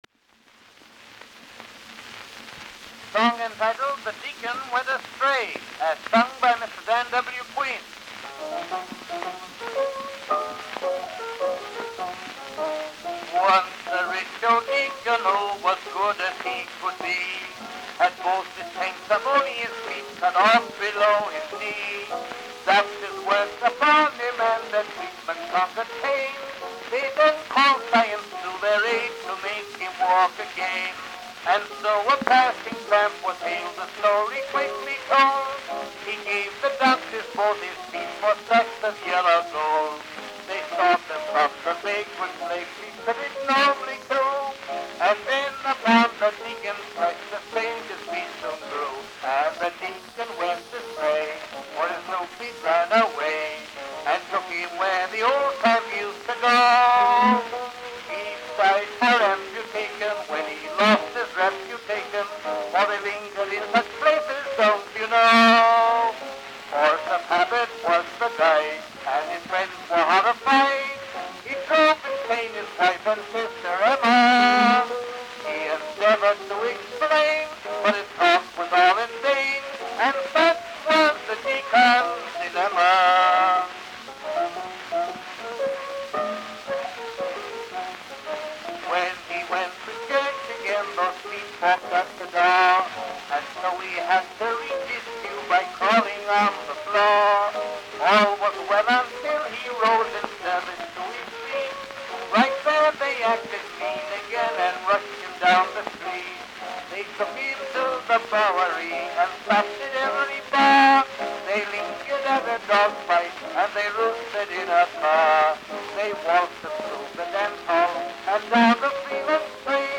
Popular music—To 1901.